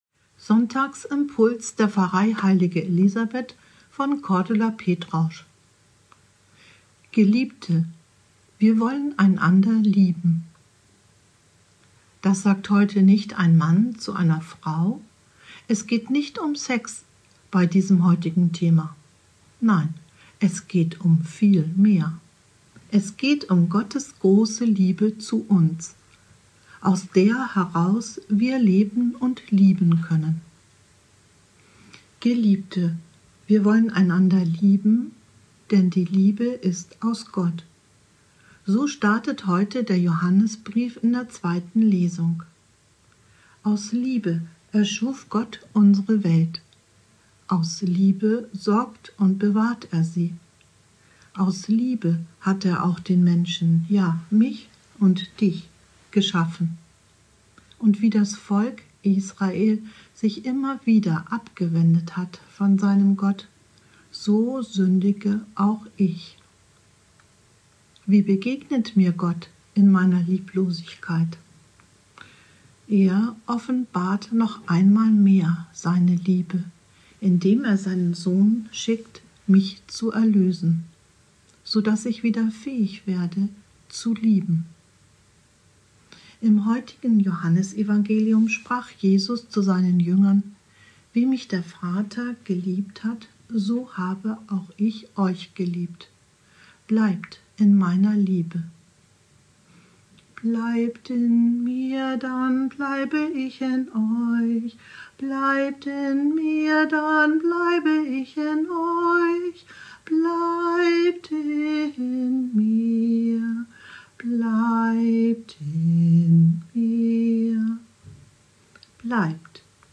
Im neunundreißigsten Beitrag der Sonntagsimpulse spricht Gemeindereferentin